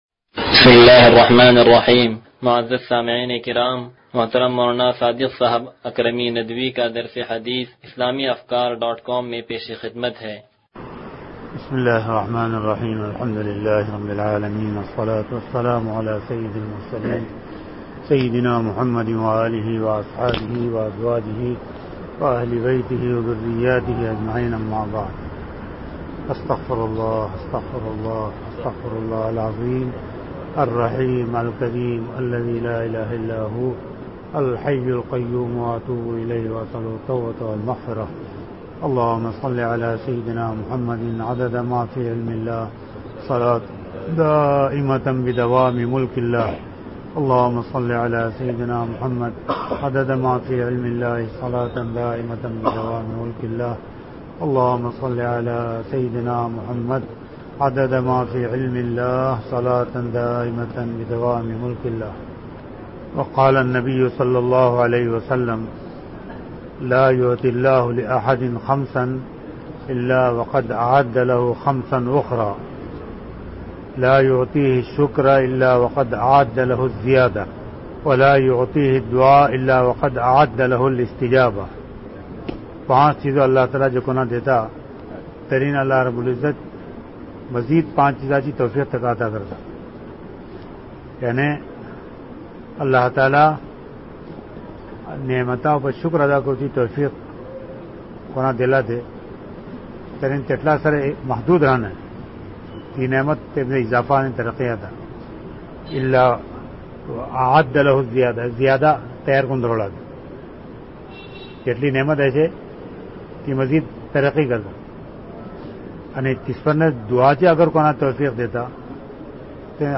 درس حدیث نمبر 0104
(تنظیم مسجد)